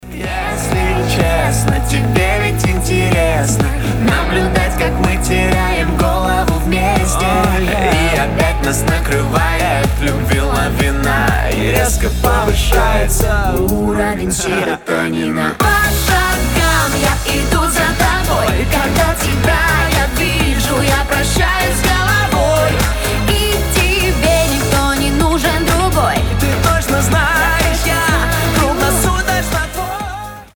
поп
дуэт